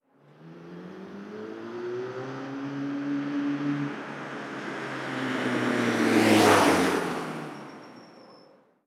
Coche pasando rápido 3
charco
coche
Sonidos: Transportes